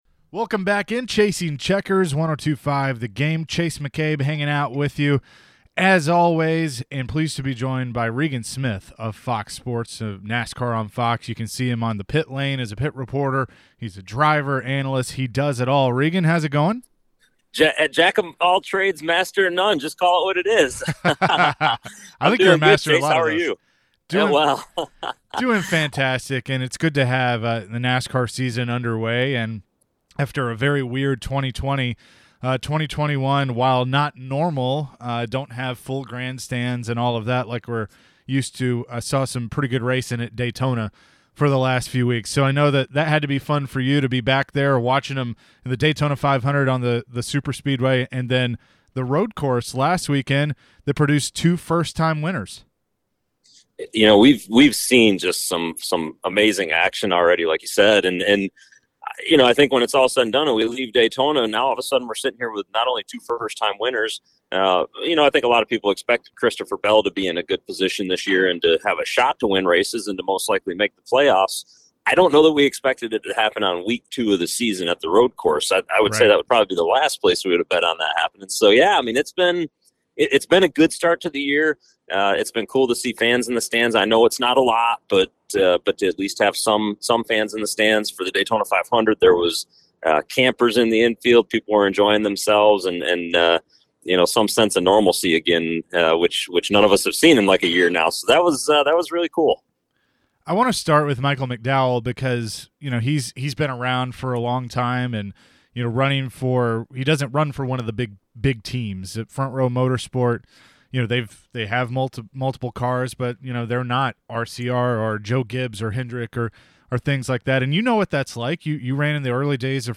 Regan Smith Interview from 2-27-21